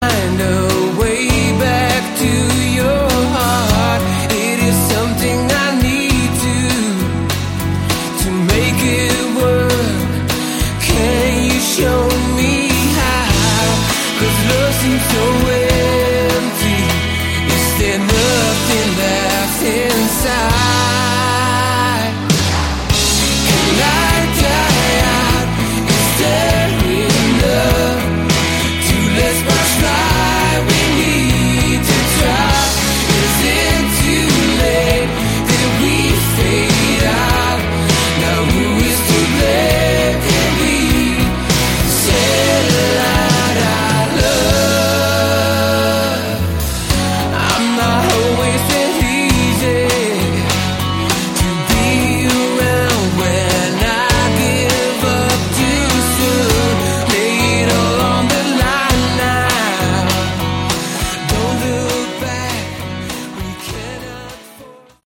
Category: AOR
lead and backing vocals
acoustic, electric guitars, keyboards
drums, percussion, synthesizers, keyboards